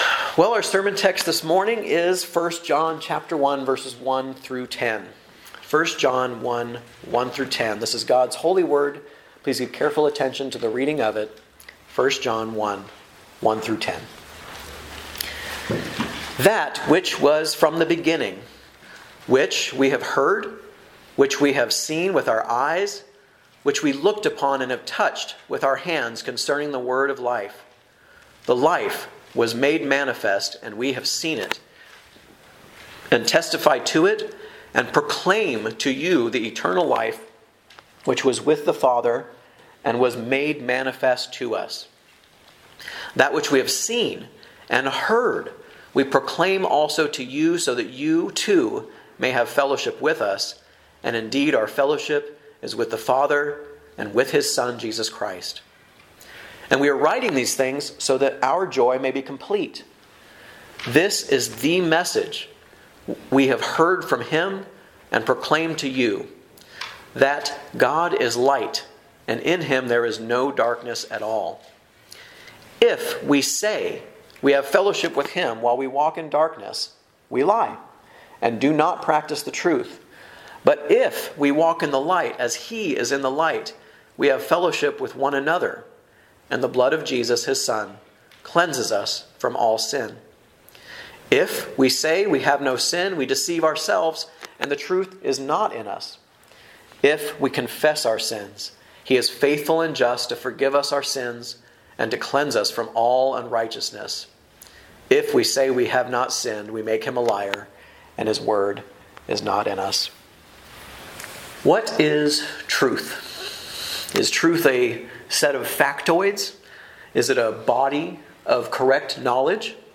A message from the series "Guest Preacher." 1: True Christ (vv1-4) the Word of Life seen, heard, and handled.